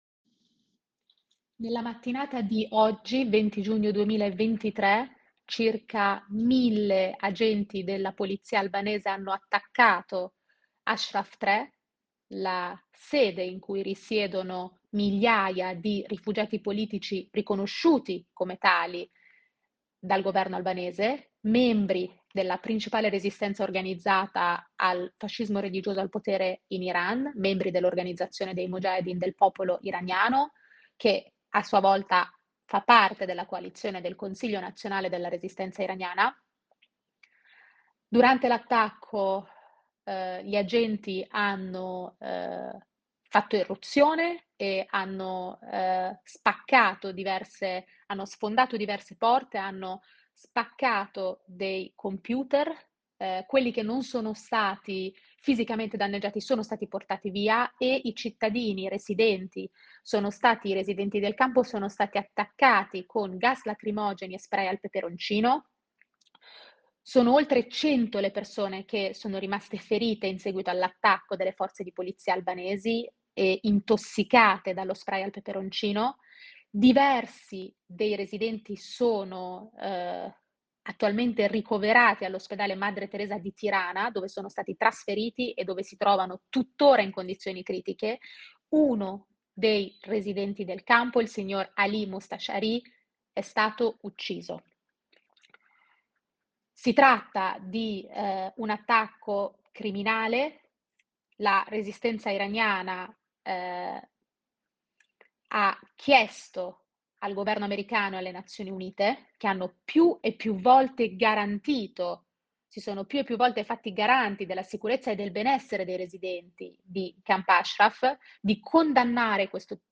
La corrispondenza di Radio Onda Rossa